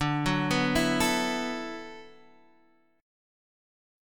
D6add9 chord